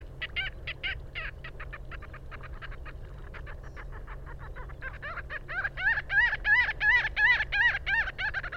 Marbled Godwit